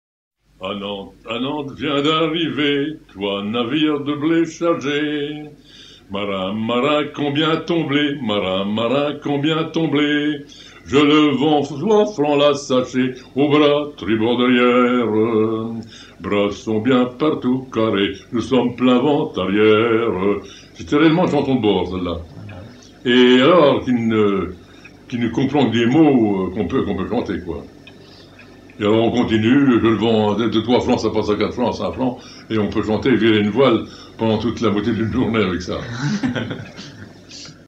Enregistrement de 1978
à virer au cabestan
Genre laisse